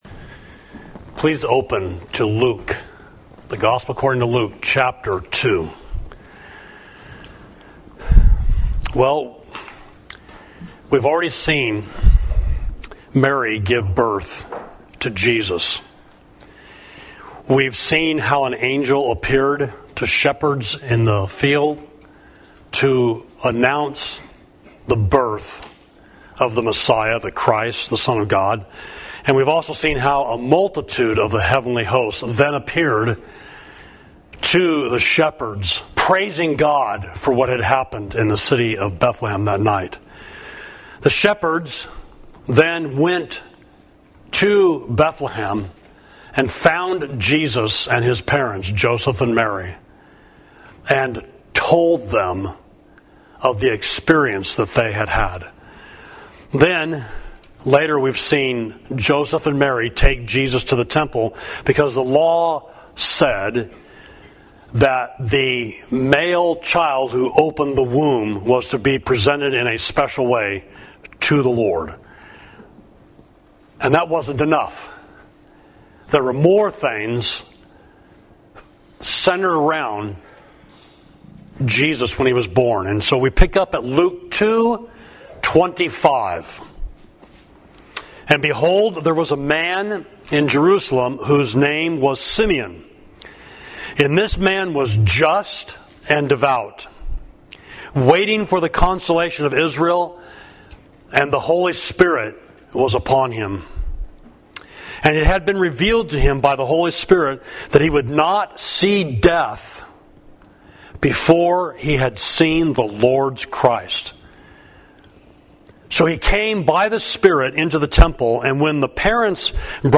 Sermon: Departing in Peace, Luke 2.25–52